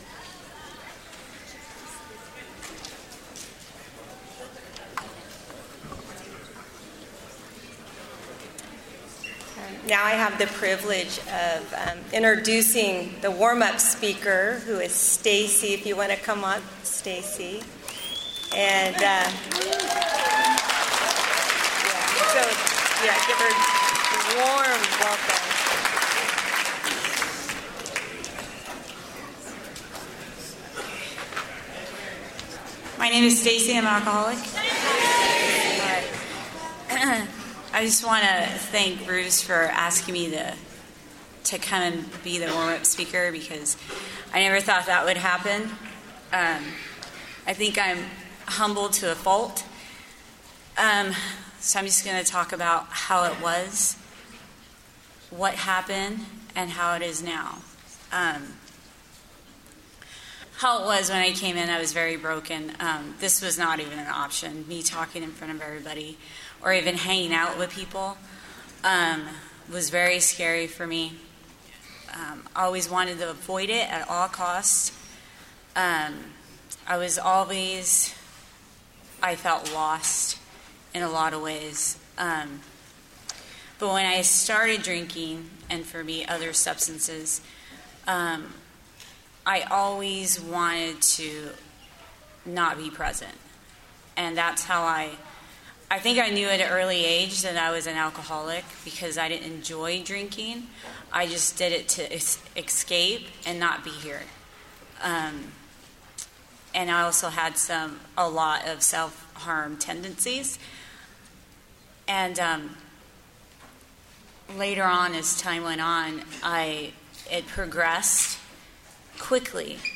Encore Audio Archives - 12 Step Recovery 15th Annual Take Your Sponsor To Dinner